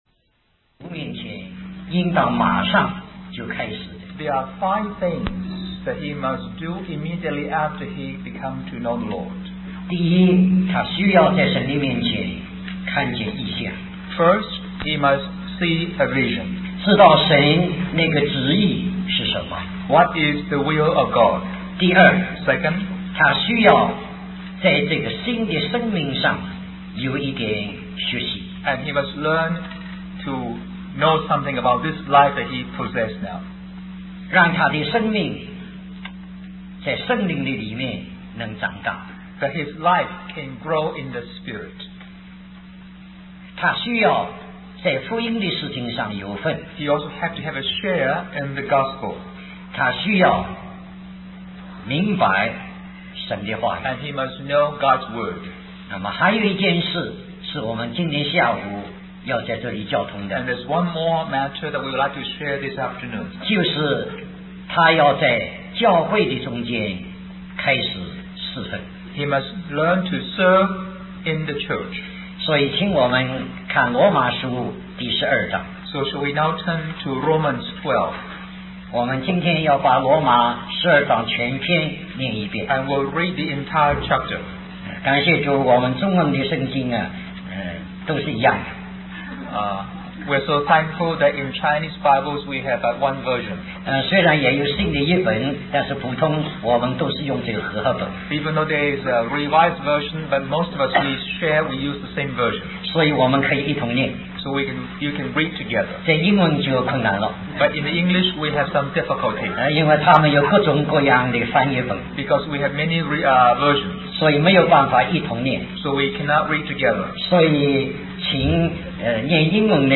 In this sermon, the speaker emphasizes the importance of five actions that a person must take after coming to know the Lord.
Lastly, they must learn to serve in the church. The speaker then refers to Romans 12, encouraging the congregation to read and study the chapter together, highlighting the significance of unity in using the same version of the Bible.